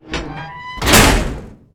door_closing.ogg